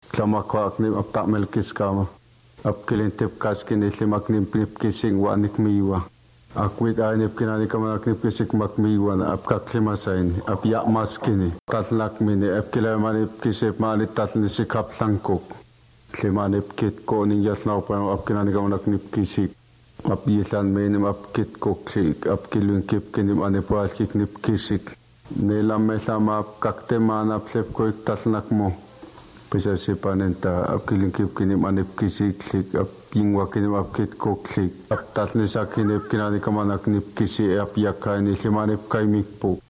Prominent lateral fricatives and affricates.
The staccato rhythm makes it sound agglutinative, perhaps very much so.